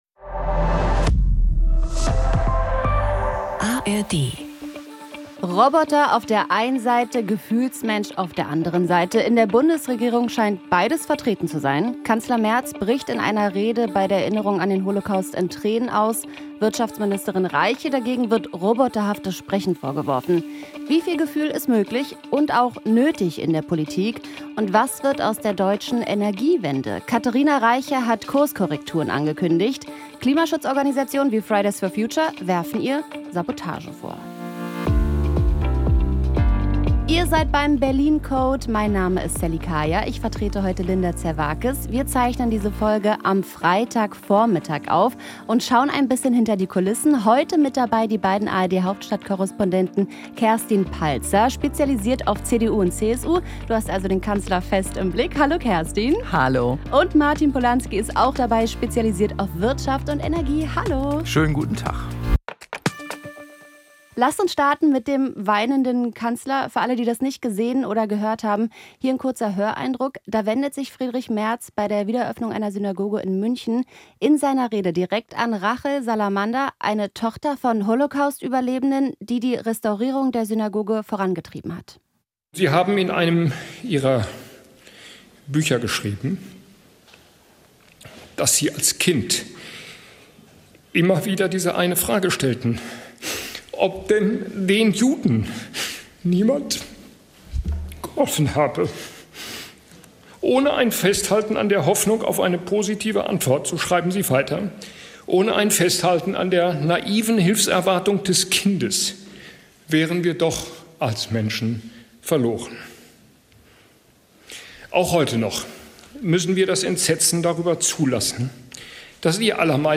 "Berlin Code" ist der Politik-Podcast aus dem ARD-Hauptstadtstudio. Linda Zervakis schaut mit den ARD-Korrespondentinnen und -korrespondenten jede Woche hinter die Kulissen der Bundespolitik.